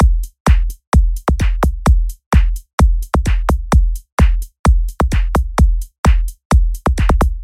描述：沉重的房子鼓循环
标签： 129 bpm Deep House Loops Drum Loops 1.25 MB wav Key : Unknown
声道立体声